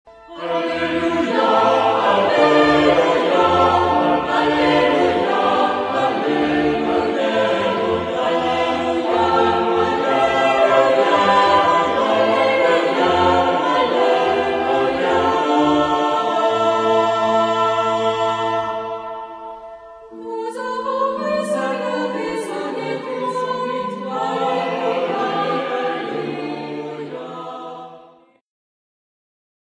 Epoque: 20th century
Genre-Style-Form: Sacred ; Acclamation
Type of Choir: SATB  (4 mixed voices )
Tonality: A minor